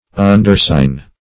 Undersign \Un`der*sign"\, v. t.